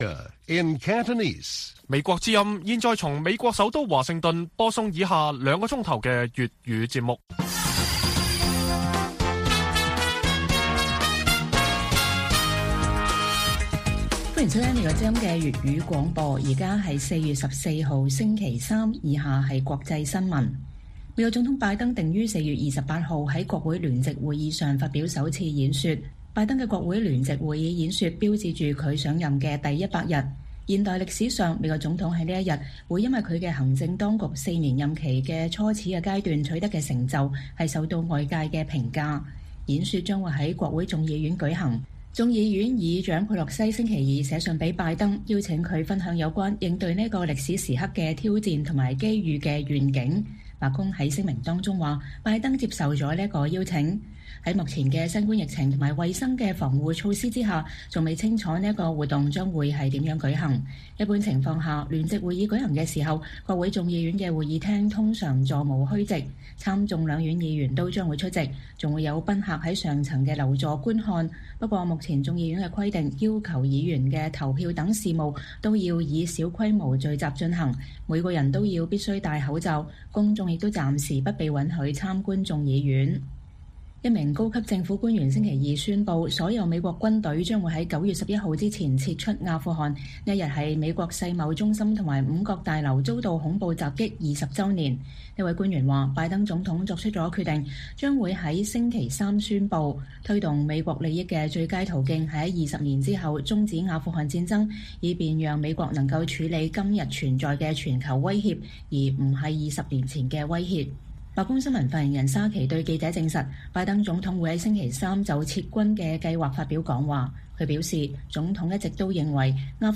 粵語新聞 晚上9-10點